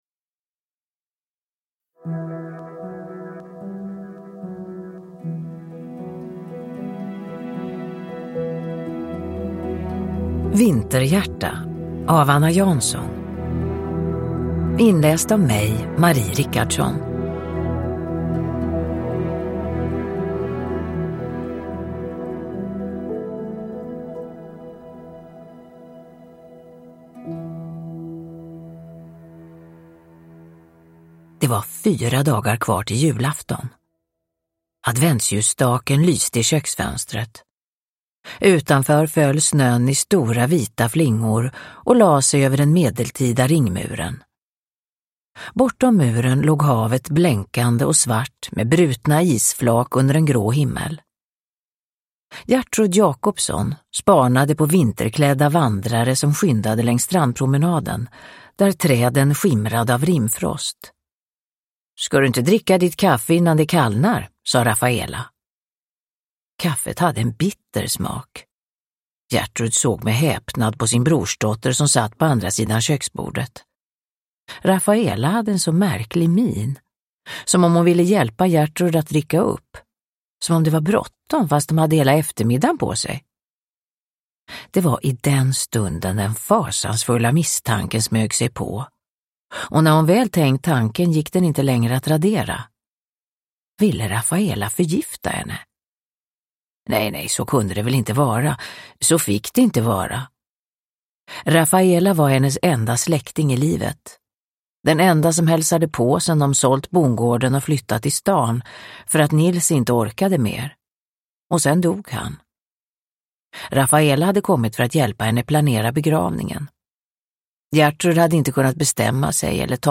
Vinterhjärta – Ljudbok
Uppläsare: Marie Richardson